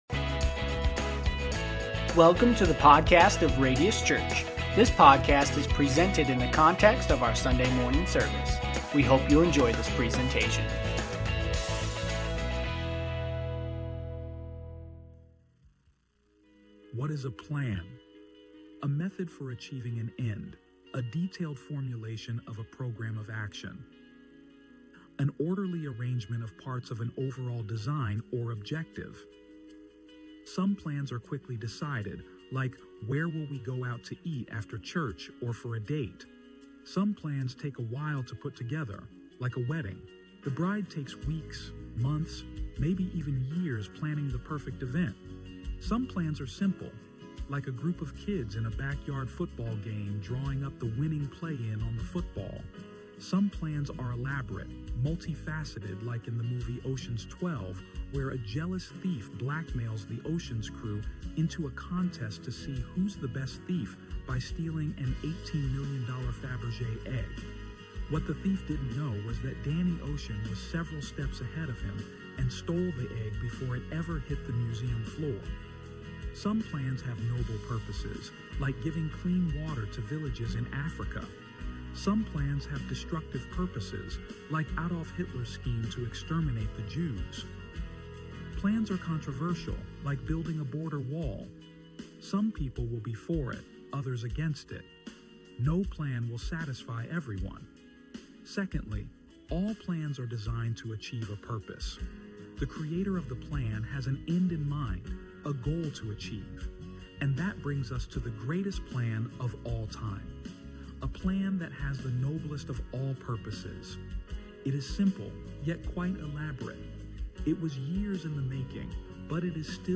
Sermons | Radius Church